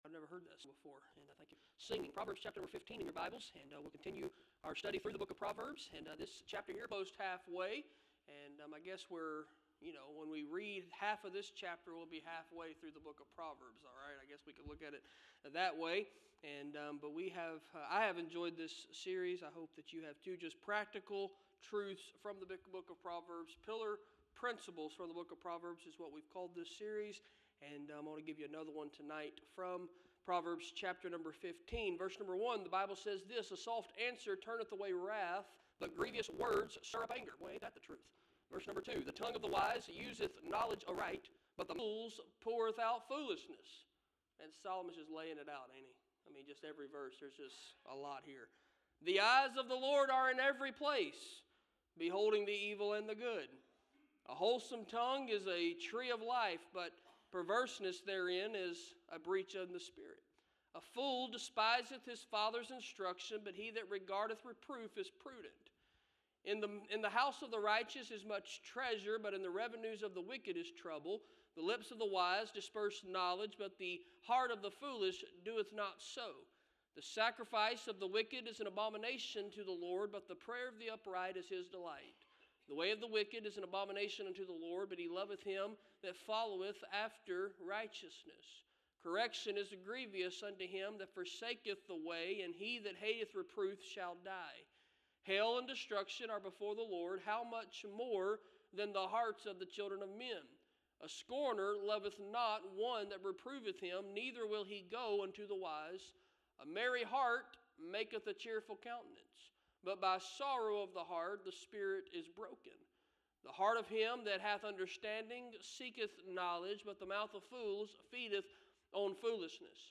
Preaching from the Pulpit | First Baptist Church